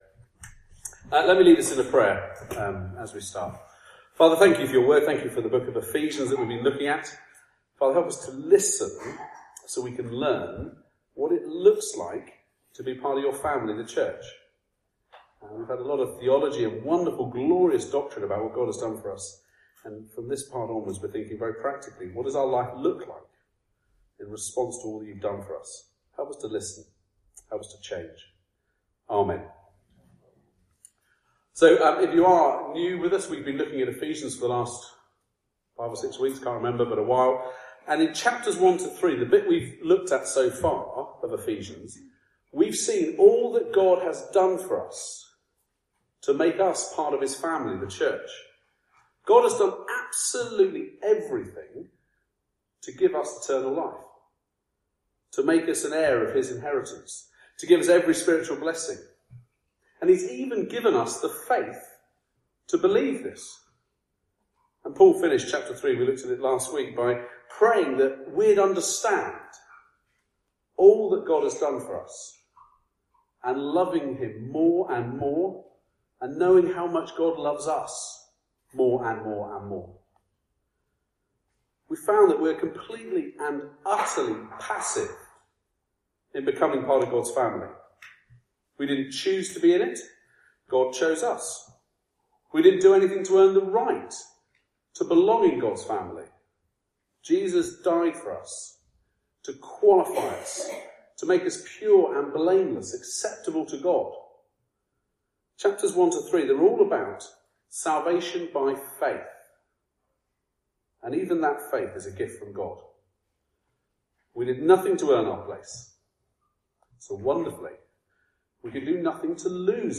Passage: Ephesians 4:1-16 Service Type: Weekly Service at 4pm
Sermon-Ephesains-4-1-16.mp3